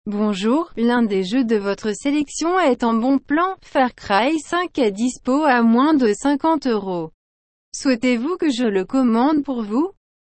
Ainsi, DDG Home va surveiller le prochain bon plan sur le jeu, et vous avertir par un message sonore dès qu’une offre sera publiée !